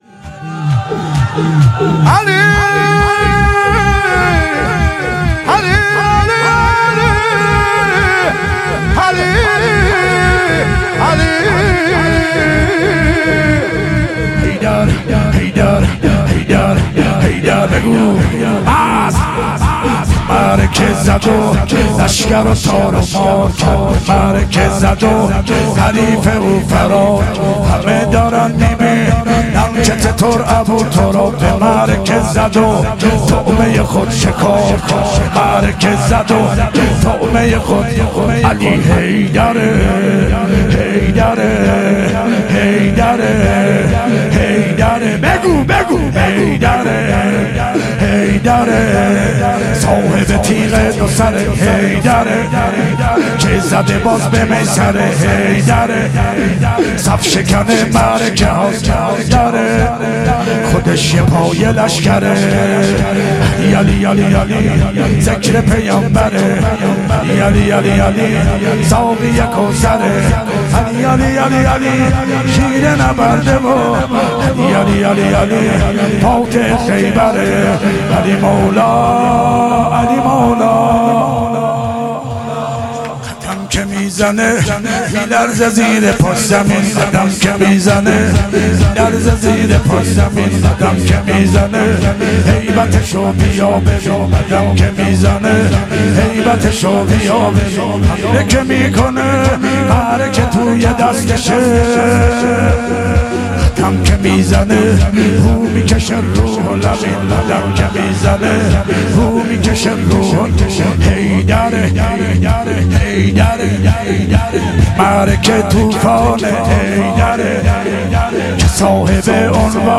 شور
دهه اول صفر 1441 شب دوم